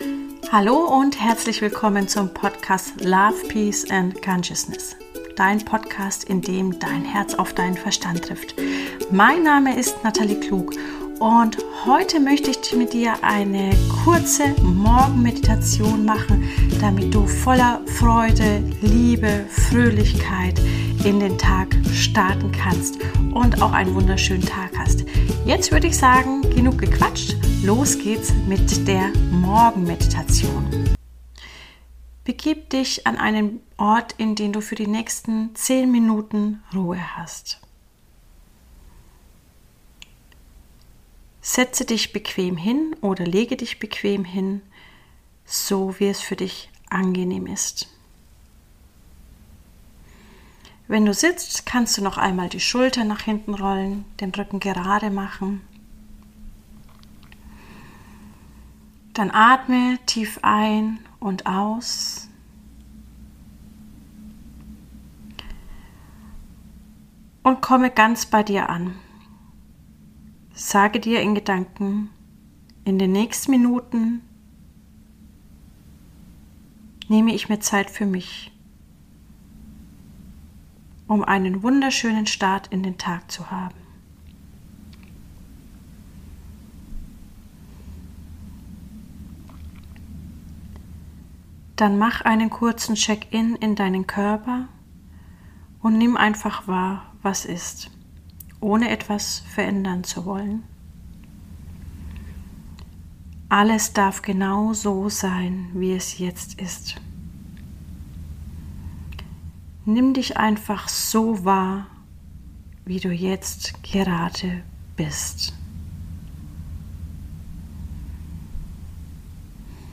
Eine Dosis Energie am Morgen - geführte Meditation